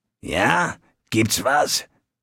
Fallout 3: Audiodialoge
Malegenericghoul_dialoguemsmini_hello_000c9ccc.ogg